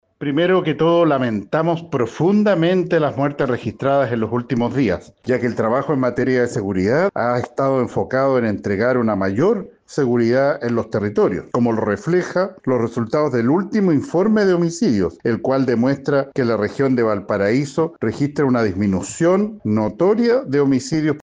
Por su parte, el delegado presidencial de la región de Valparaíso, Yanino Riquelme, lamentó a nombre del gobierno los homicidios registrados en la zona.